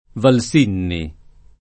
Valsinni [ val S& nni ]